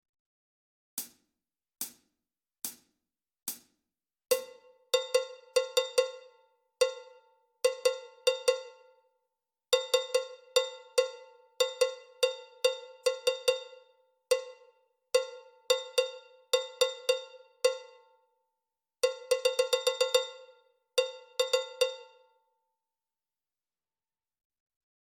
lecture rythmique1